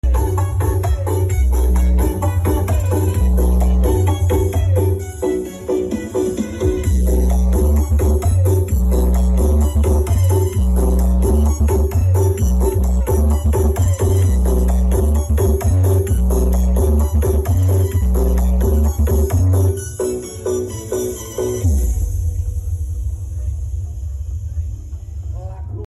Berkah sholawat karnaval kedawung nglegok sound effects free download